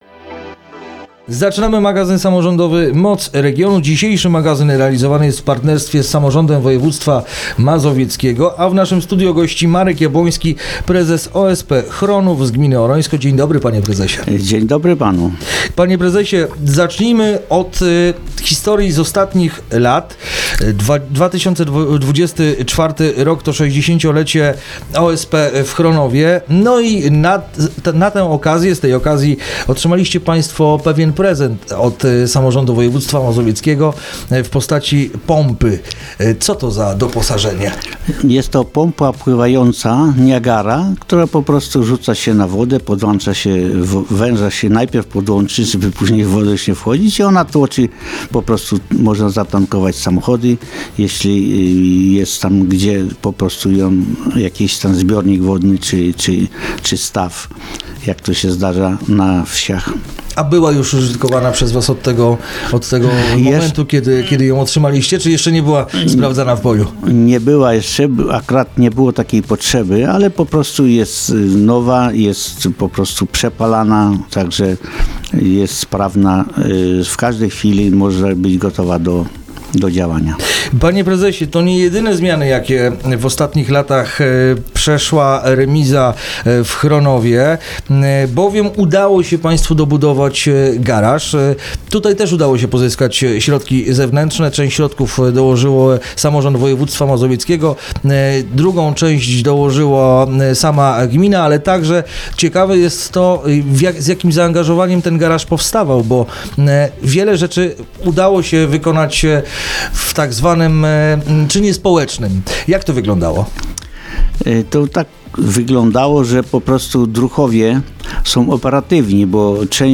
Rozmowa dostępna jest także na facebookowym profilu Radia Radom: